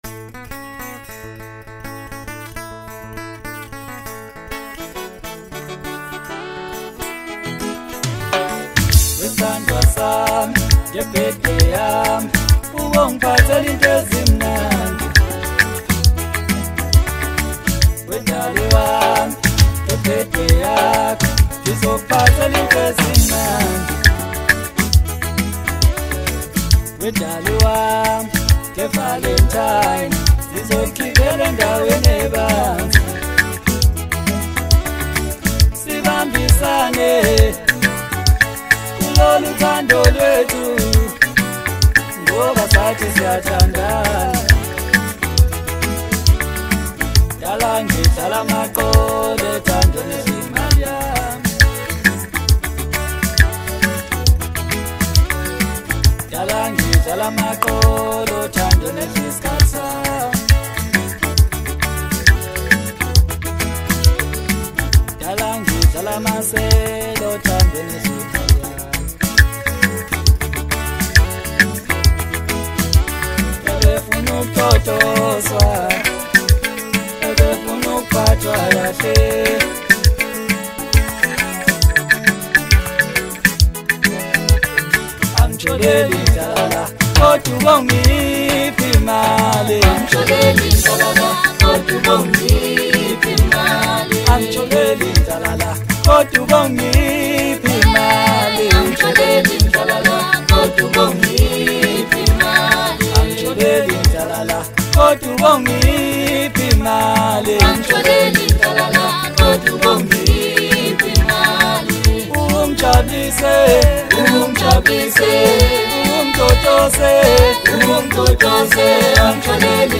Home » Hip Hop » Latest Mix » Maskandi
HOTTEST MASKANDI SONGS!!!